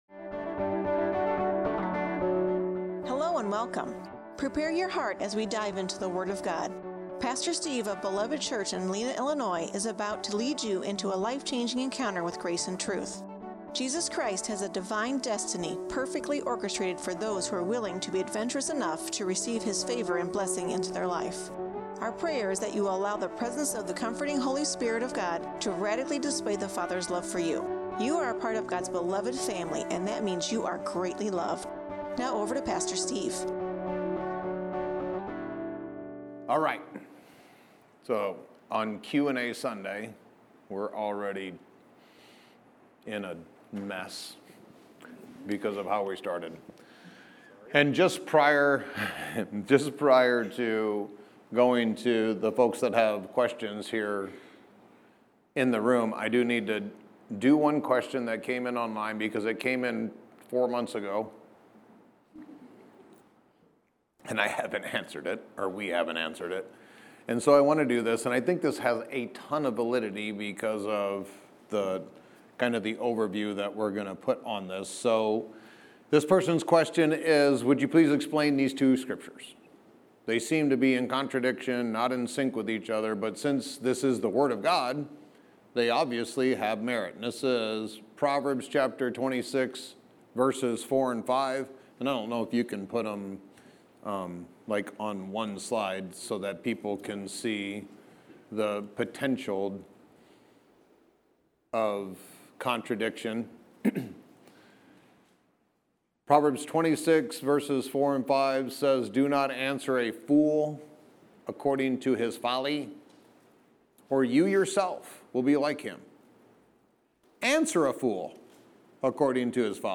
Q & A Sunday